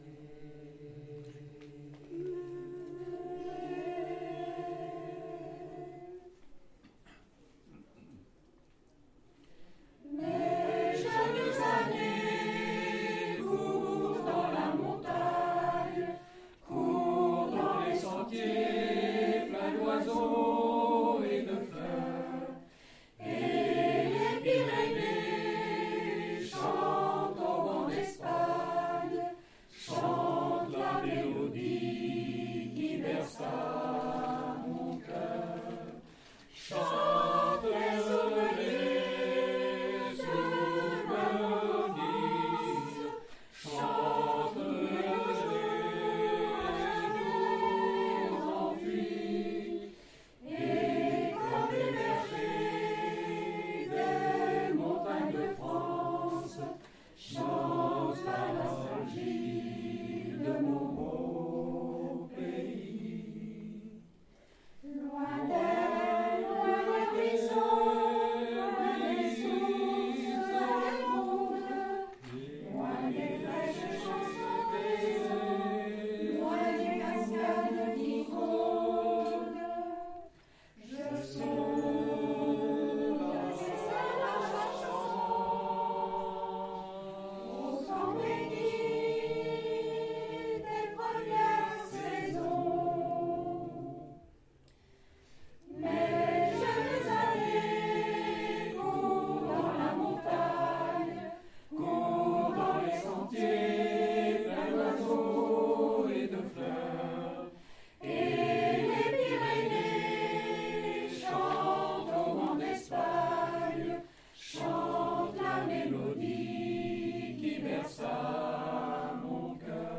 Cess'tival 2025 le 21 juin à Cesson-Sévigné à la médiathèque du Pont des Arts